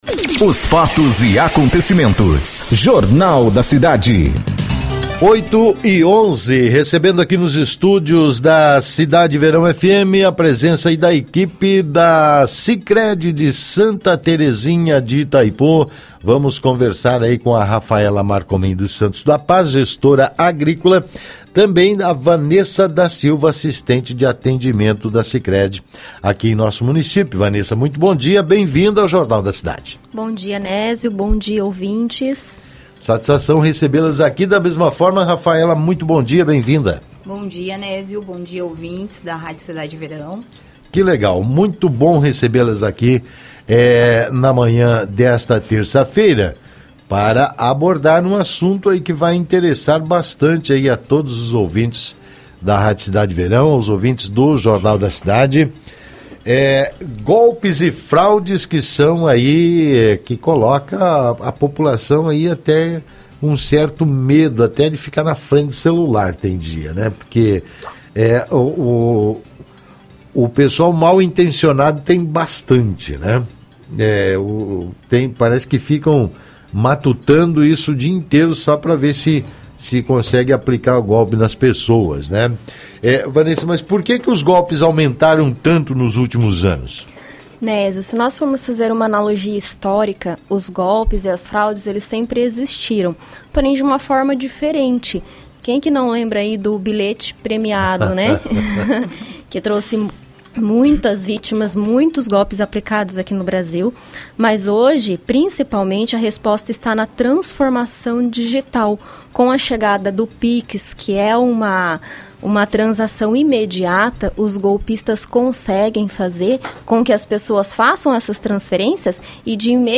Cooperativa Sicredi alerta sobre fraudes e golpes em entrevista ao Jornal da Cidade